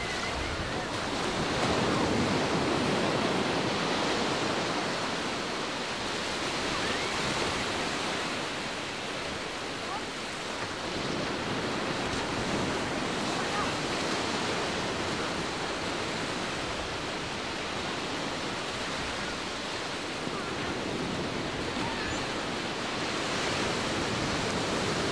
海洋风暴
描述：聆听海风的声音，感受大自然的力量。 正念，精神慰借，放松
标签： 沙滩 海浪 海洋 海滨 性质 现场录音
声道立体声